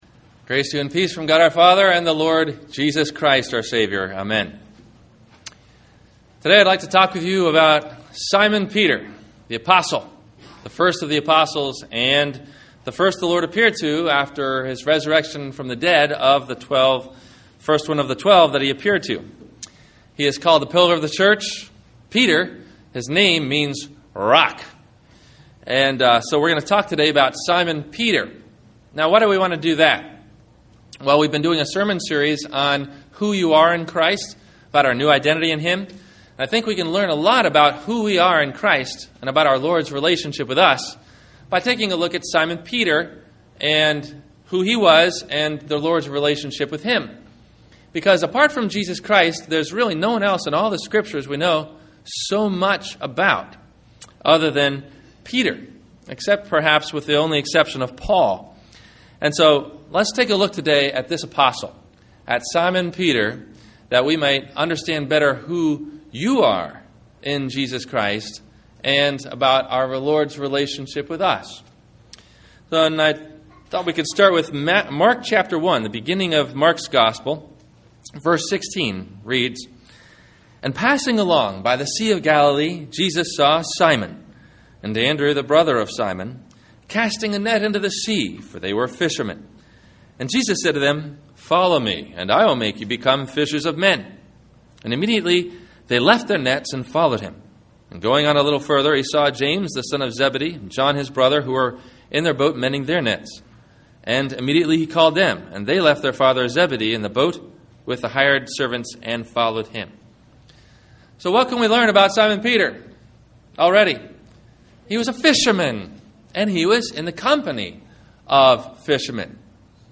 Peter and The Wolf – Sermon – August 15 2010